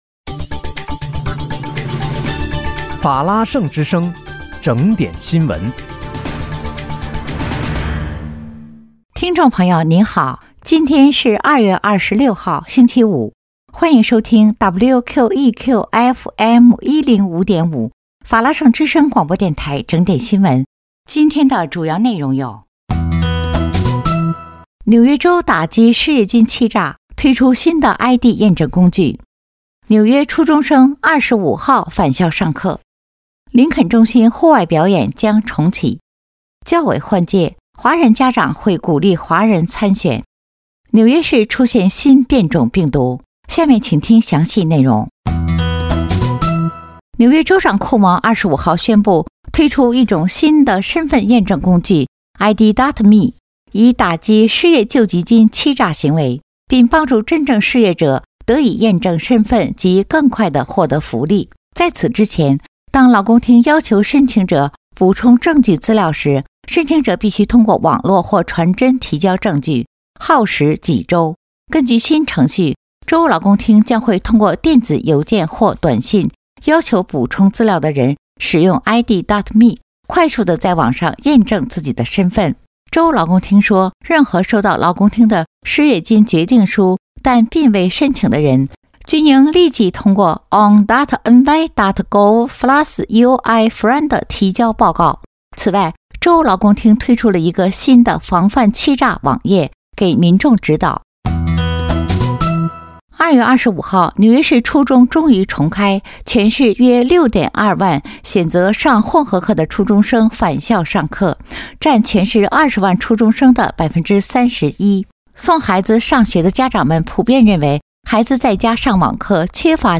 2月26日（星期五）纽约整点新闻